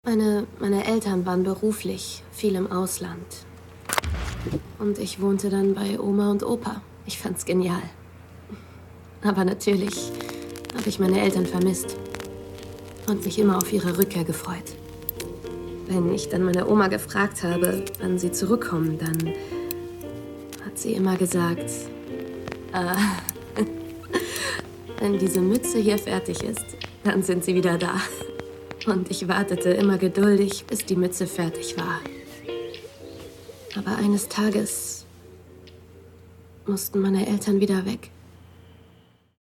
Fränkisch
Synchron Monolog